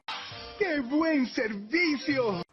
QUE BUEN SERVICIO - Tono movil
Que_buen_servicio.mp3